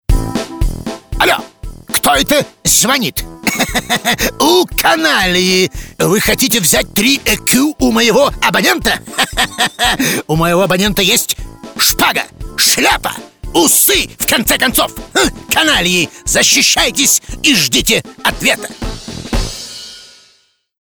Parodiya_na_Boyarskogo.mp3